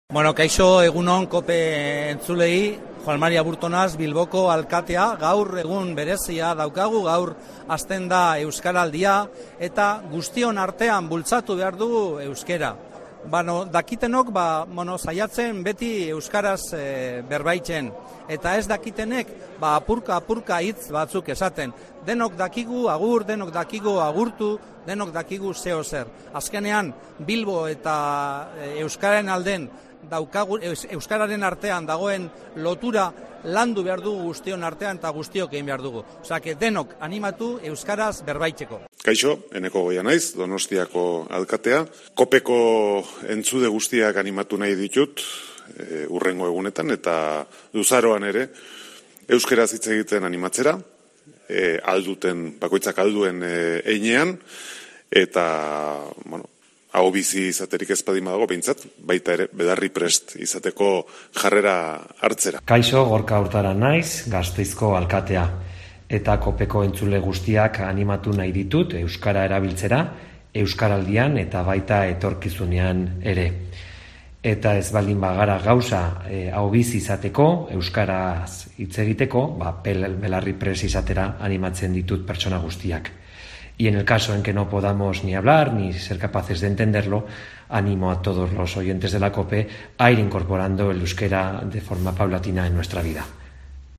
Los alcaldes de Bilbao, San Sebastián y Vitoria animan a usar el euskera